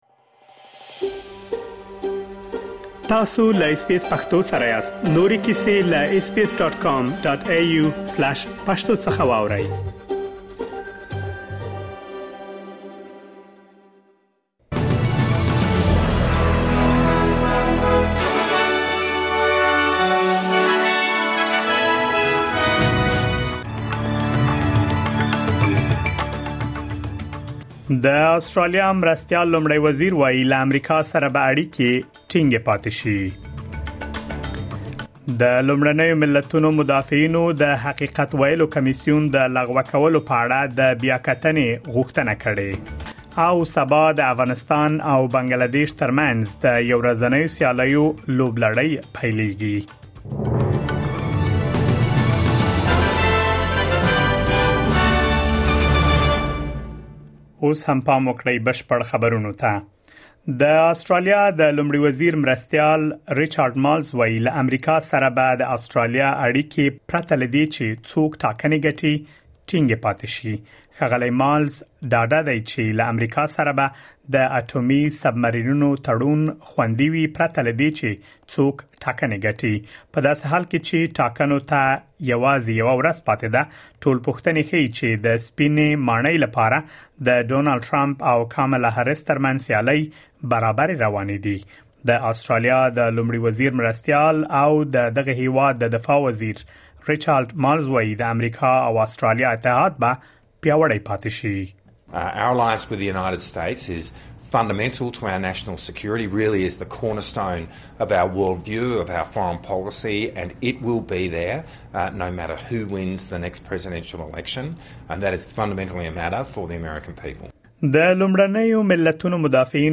د اس بي اس پښتو د نن ورځې لنډ خبرونه |۵ نومبر ۲۰۲۴
د اس بي اس پښتو د نن ورځې لنډ خبرونه دلته واورئ.